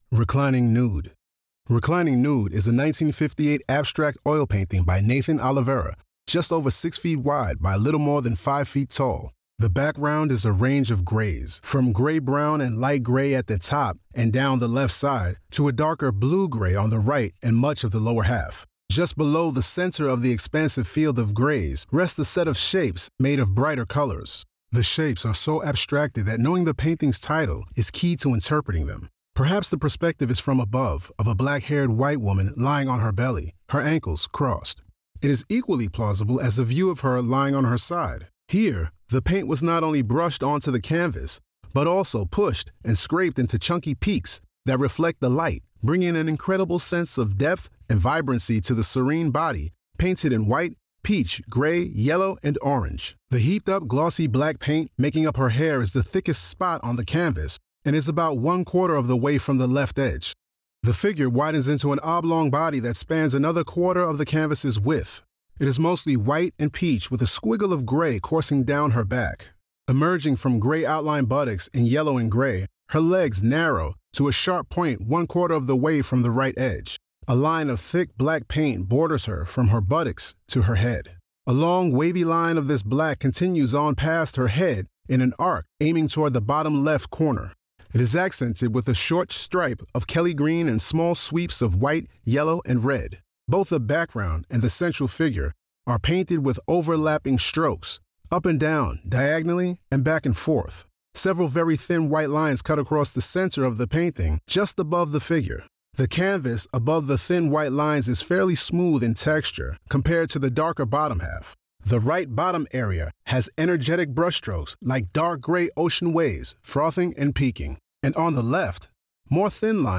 Audio Description (02:04)